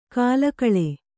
♪ kālakaḷe